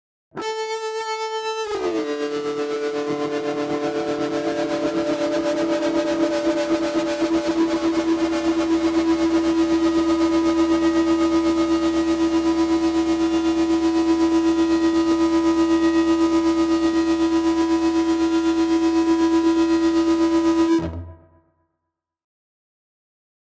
Rock Guitar Distorted FX 5 - Long Sustain Tone 2